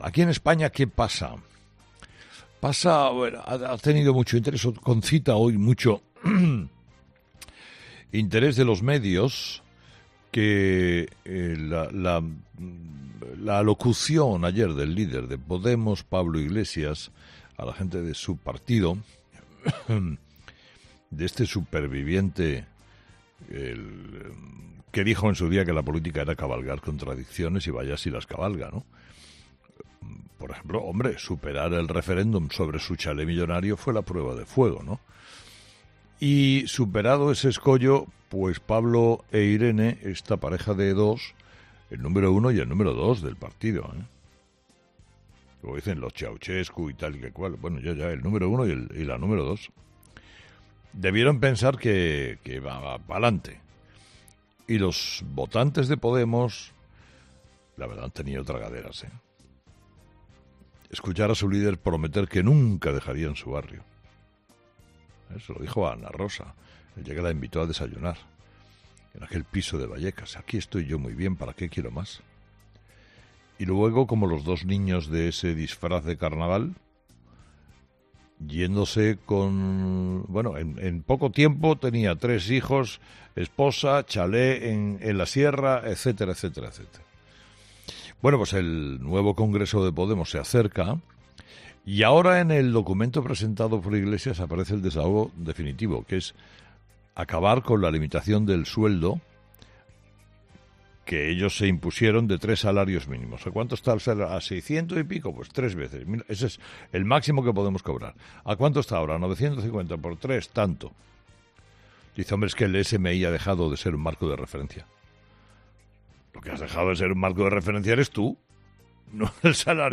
Sobre todo esto se ha pronunciado Carlos Herrera en su monólogo de las 06.00, en el que, después de tratar las últimas noticias del coronavirus, se ha referido a Iglesias y sus contradicciones.